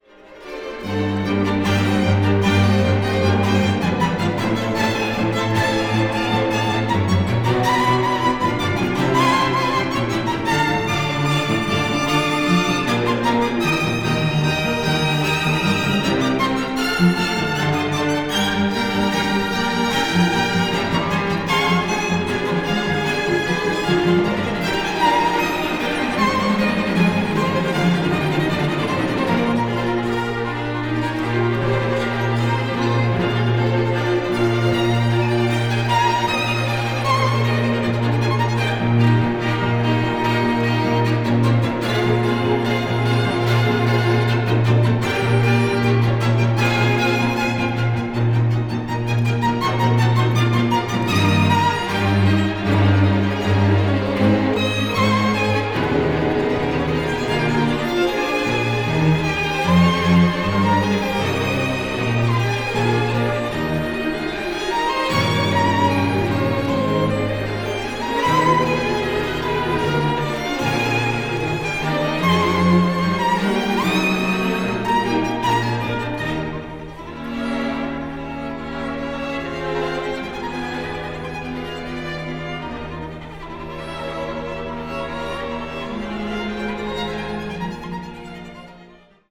MX2424 Millennia HV-3d & Avid 002 Avalon 747
DPA 4006 Schoeps MK
MSU - The Wharton Center, Great Hall .